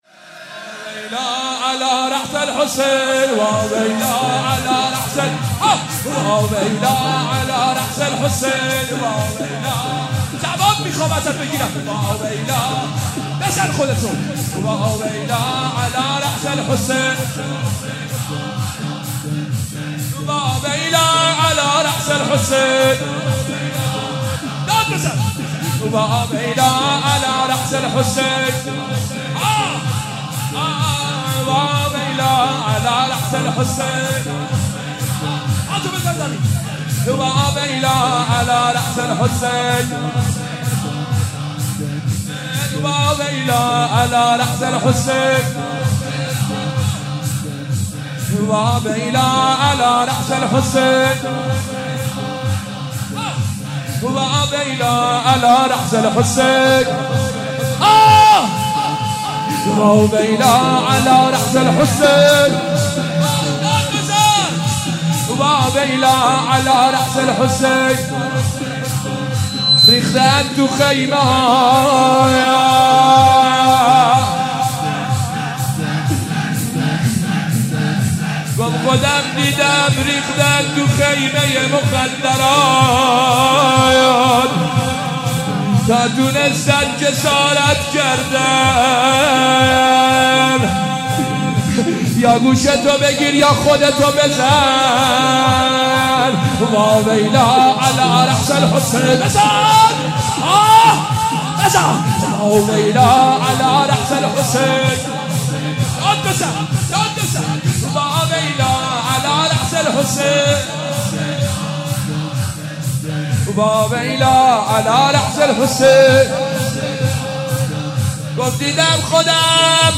مداحی فاطمیه 1396
(شور)